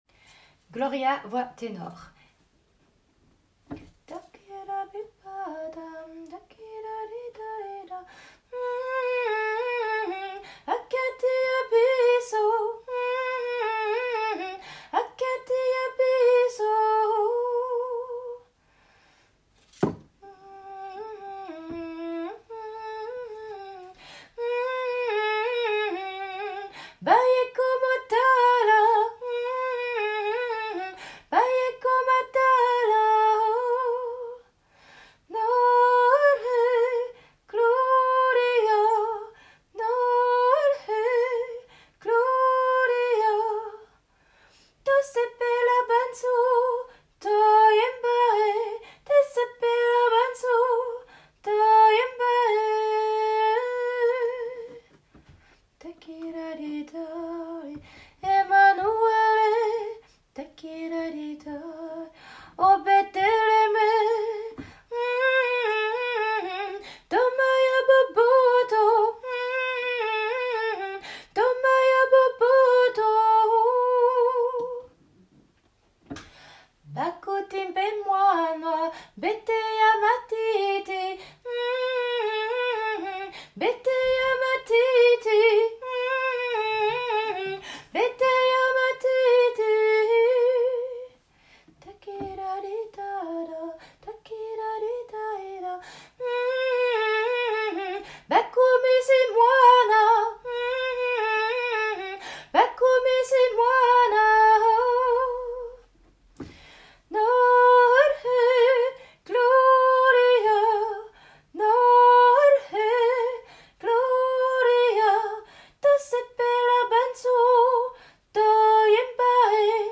Ténor
No--l-Gloria---T--nor.m4a